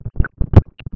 Discurso del Presidente Dr. Jorge Batlle Ibáñez en la Apertura del Congreso